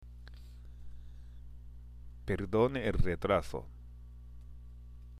（ペルドーネ　エル　レトラソ）